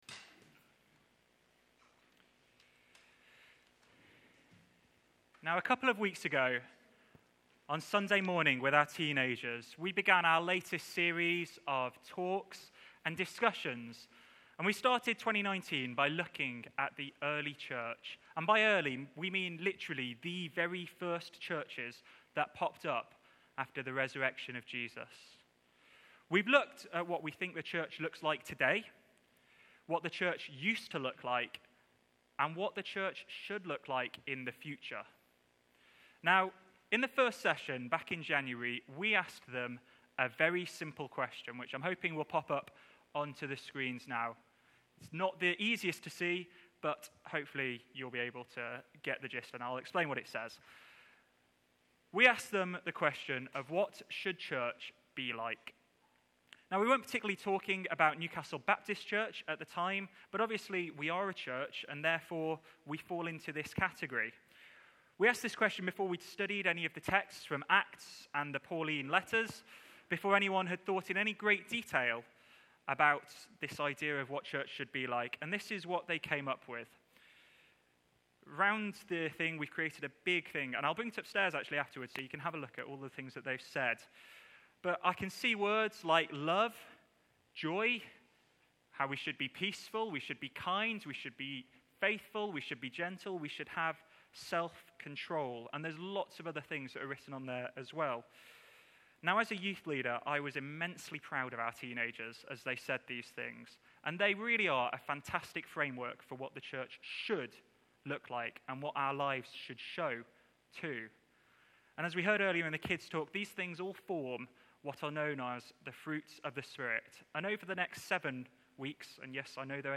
The first sermon in the series ‘Fruit of the Spirit’